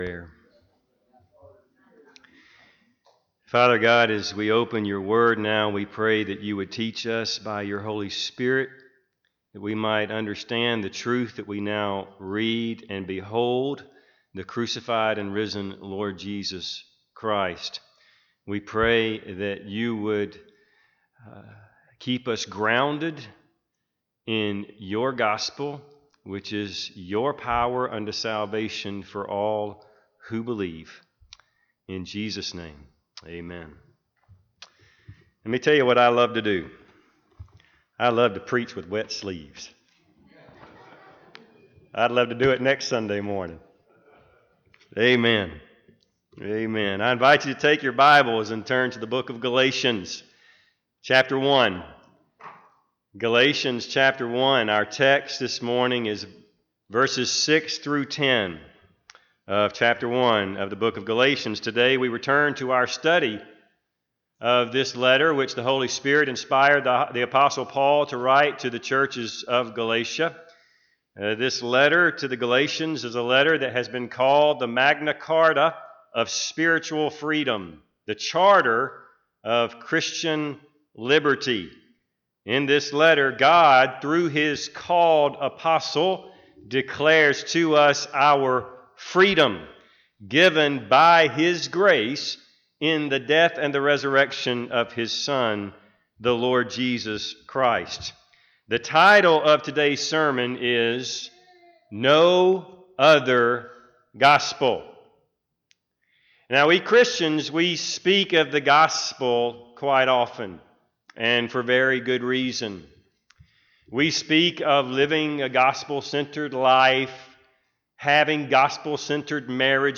Galatians 1:6-10 Service Type: Sunday AM Bible Text